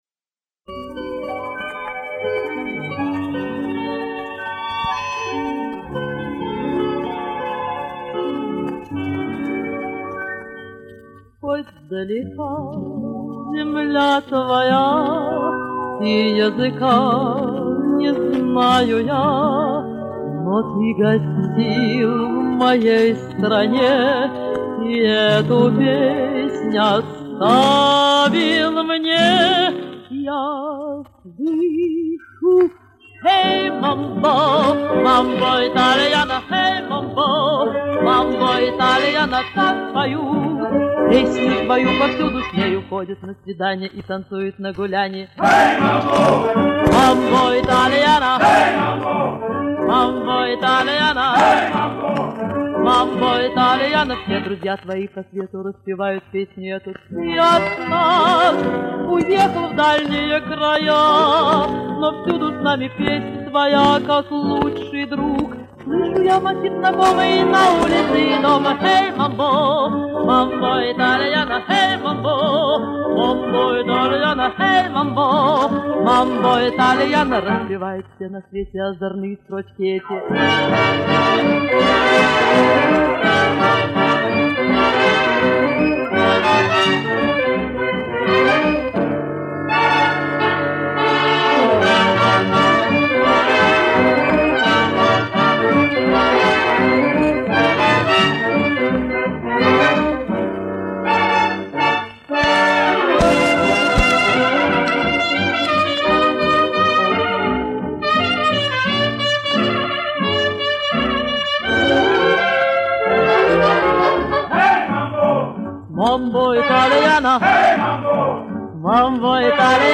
Немного почистил от тресков